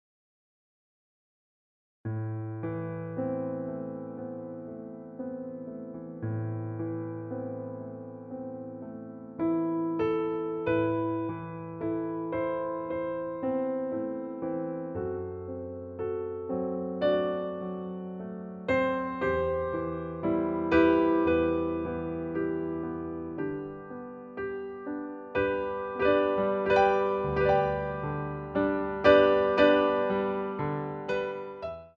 ADAGE IN THE CENTRE